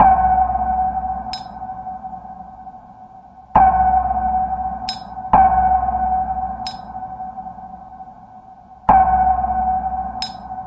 Ambient Groove " Ambient Groove 001
描述：为环境音乐和世界节奏制作。完美的基础节拍。
Tag: 环境